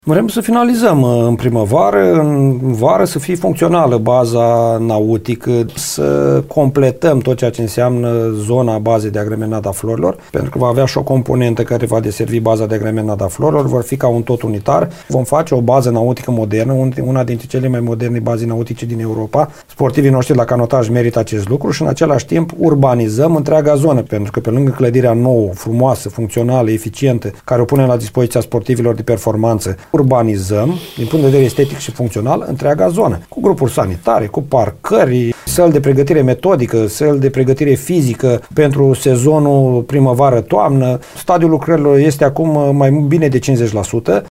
Primarul CĂTĂLIN COMAN a declarat postului nostru că – la finalul investițiilor – baza nautică de pe malul iazului Șomuz va fi una dintre cele mai moderne din Europa.